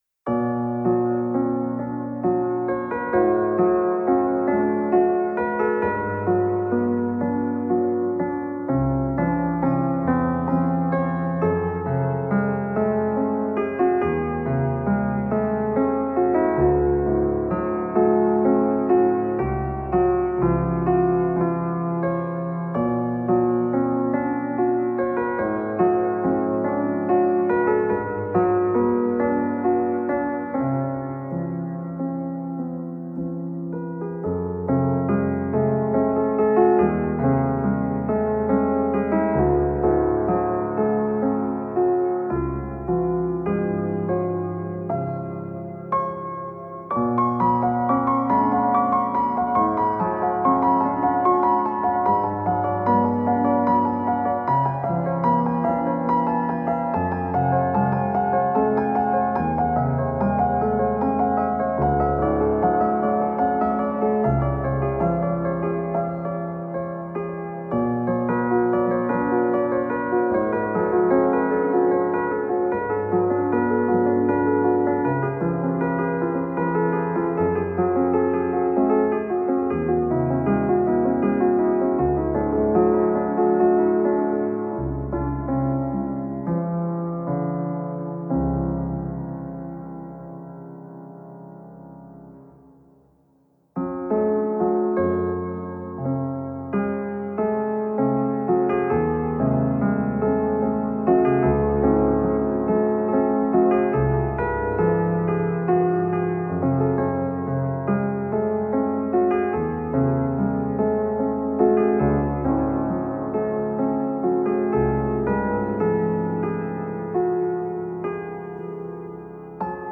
آلبوم موسیقی بی‌کلامی که با پیانو نواخته است